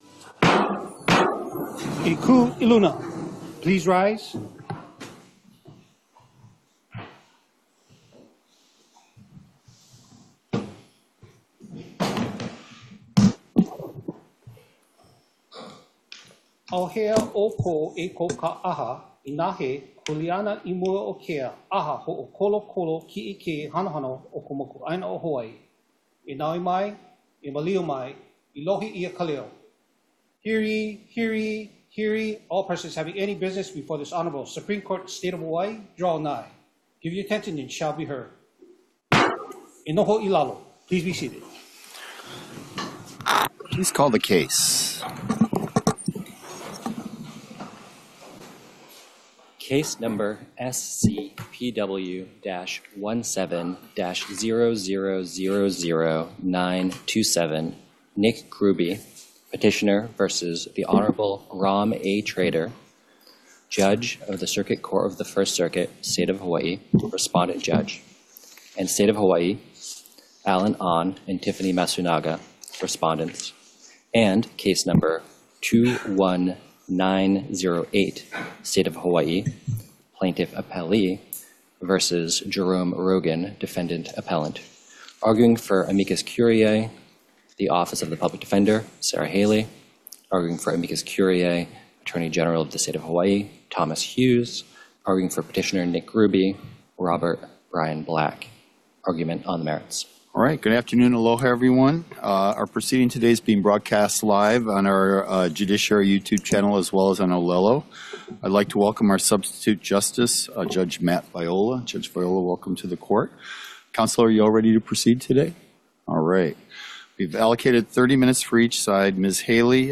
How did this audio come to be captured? The above-captioned case has been set for oral argument on the merits at: Supreme Court Courtroom Ali ‘ iōlani Hale, 2 nd Floor 417 South King Street Honolulu, HI 96813